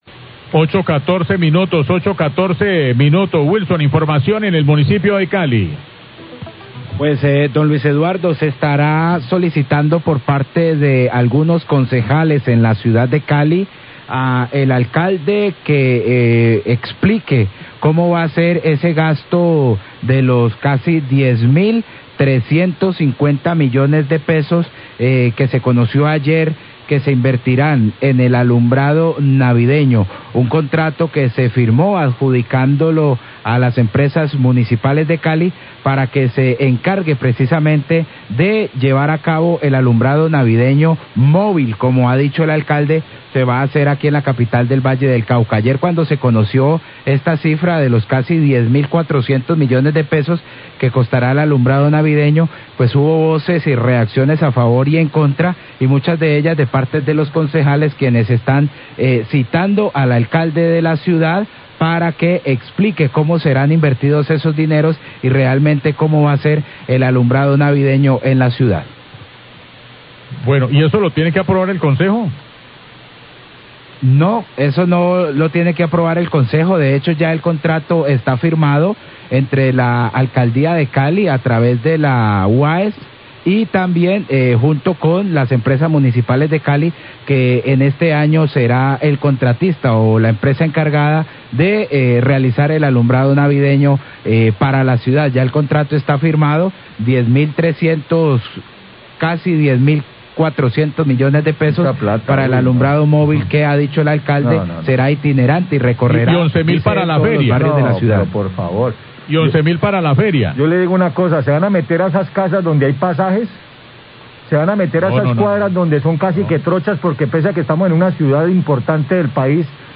Radio
Voces en rechazo de Concejales al anuncio del Alcalde de Cali sobre la firma del contrato con Emcali y la UAESPM por la realización del alumbrado público navideño móvil. Periodistas del noticiero critican el altos costo de esta iluminacón y el riesgo de contagio por la aglomeración de personas en los barrios para verlo.